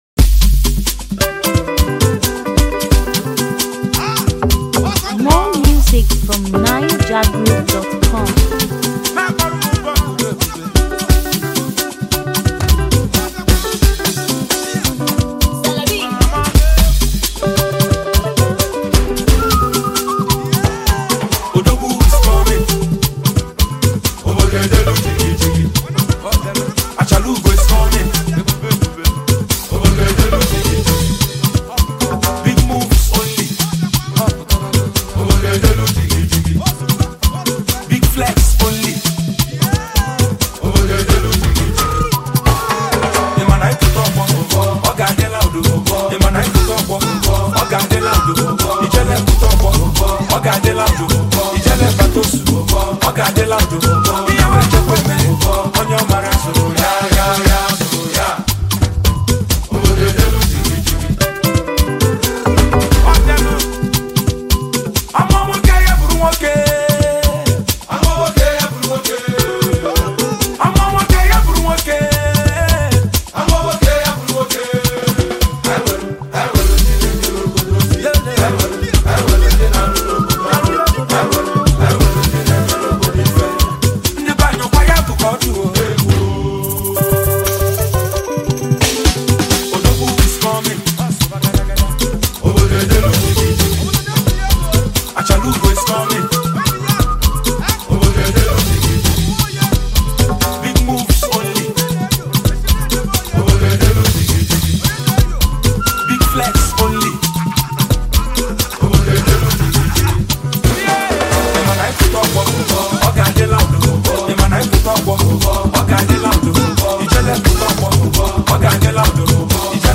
warm, textured record